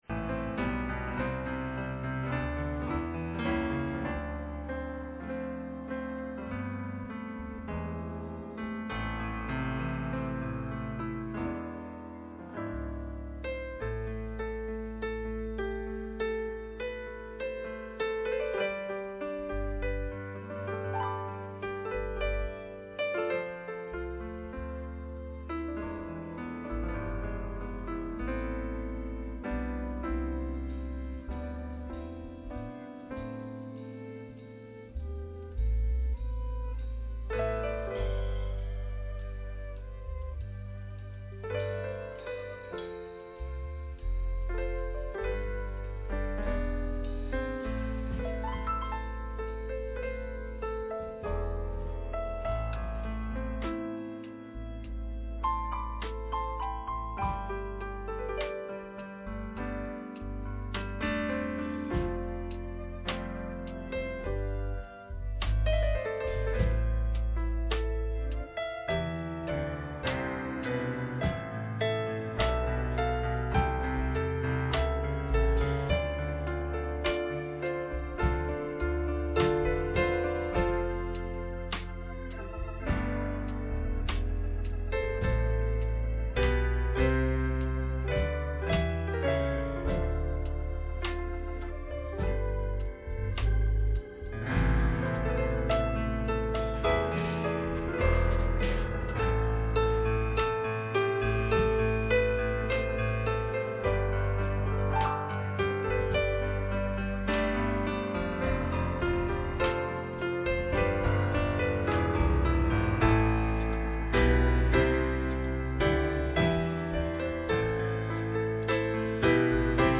Ecclesiastes 3:8 Service Type: Friday Night %todo_render% « Ephesians Chapter 4